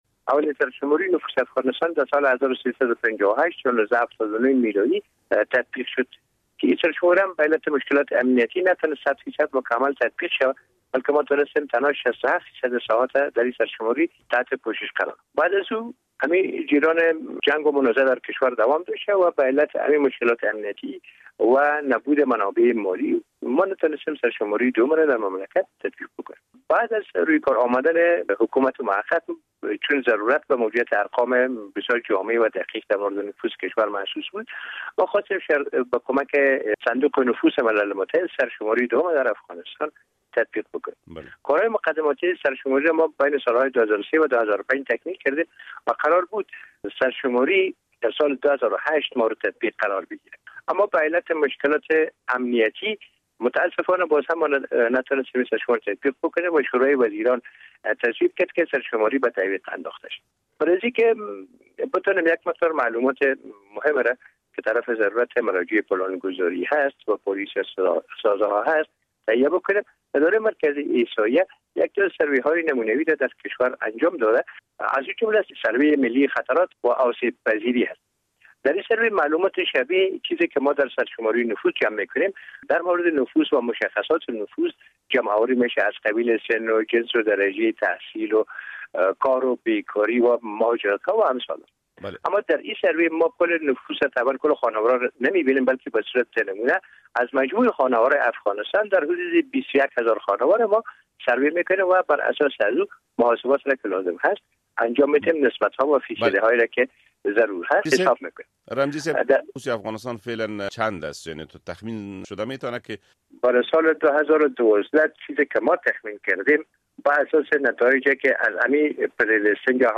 مصاحبه